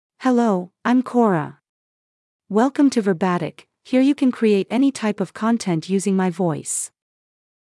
Cora — Female English (United States) AI Voice | TTS, Voice Cloning & Video | Verbatik AI
Cora is a female AI voice for English (United States).
Voice sample
Listen to Cora's female English voice.
Female
Cora delivers clear pronunciation with authentic United States English intonation, making your content sound professionally produced.